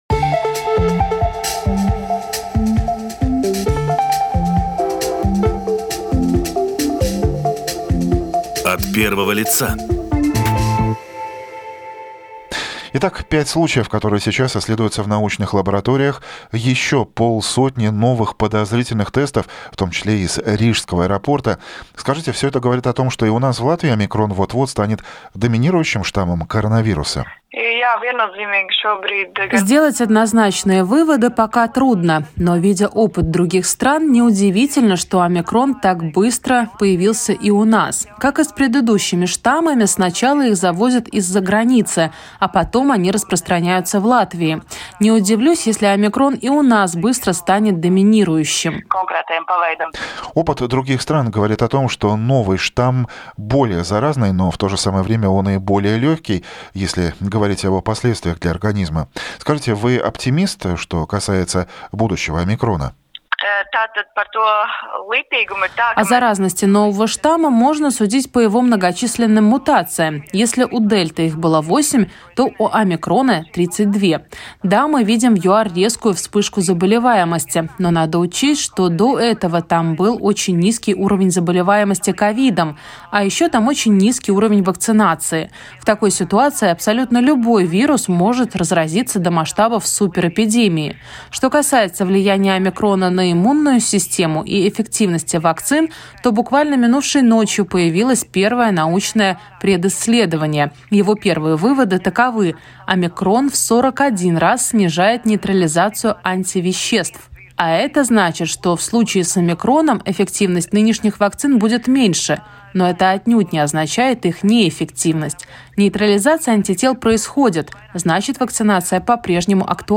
Больше подробностей - в большом интервью "Домской площади" сегодня в 8:40.